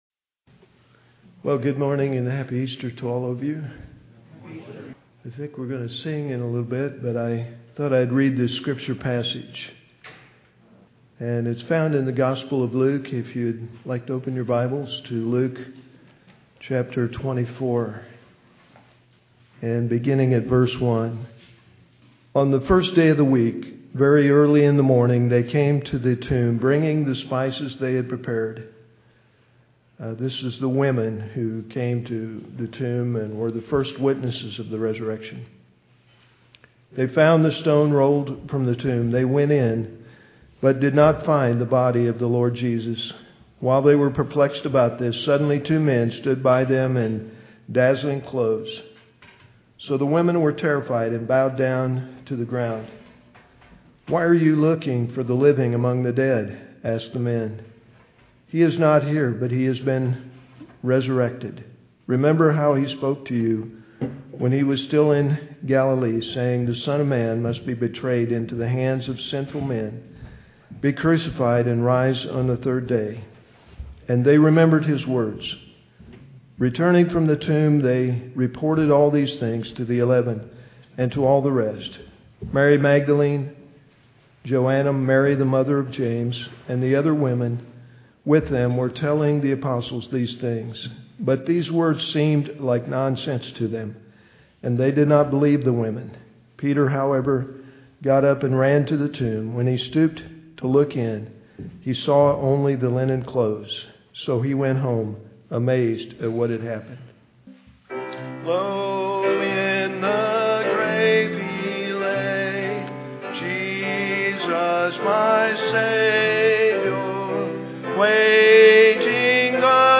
PLAY Easter Sunrise Service, Apr 12, 2009 Scripture: Luke 24:1-9.
Piano
guitar and vocal solo